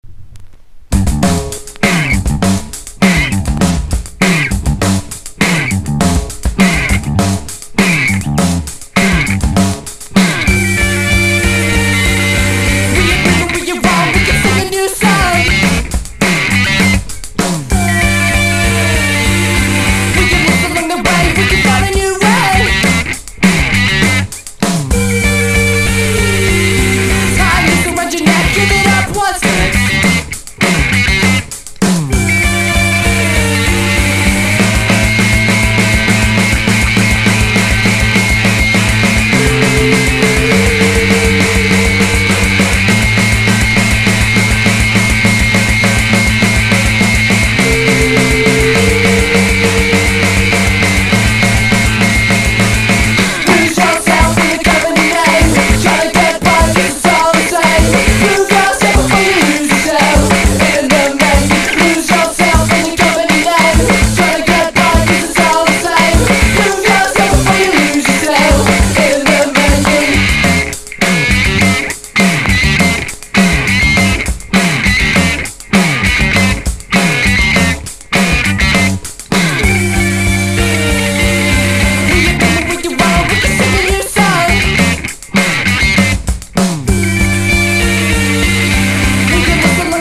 1. 00S ROCK >
# ALTERNATIVE / GRUNGE